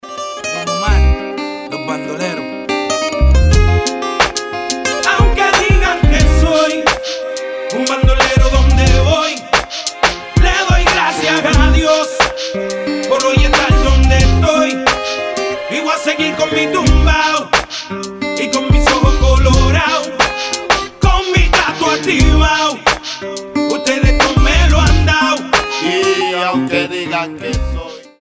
Хип-хоп
Латиноамериканские
Реггетон